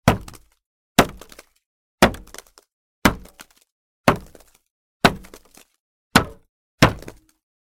Звуки стены: Стук по деревянной поверхности